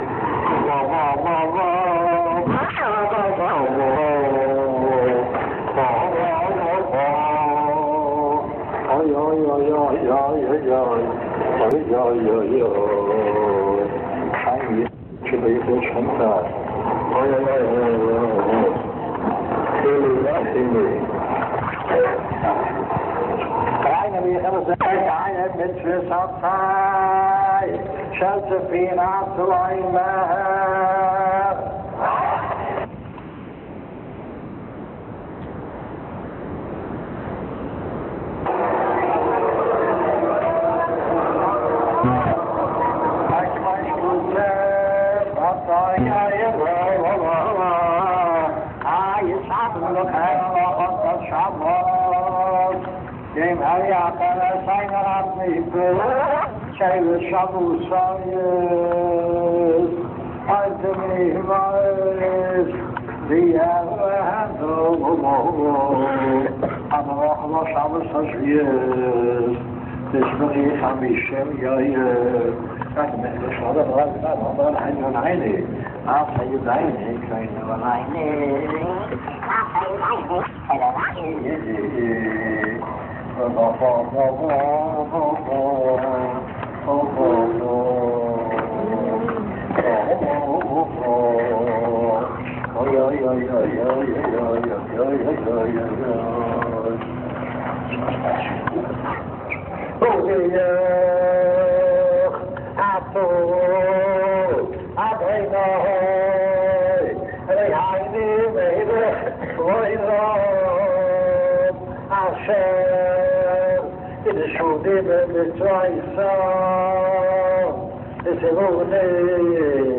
ארכיון: קולם בקודש של רבוה"ק בספירת העומר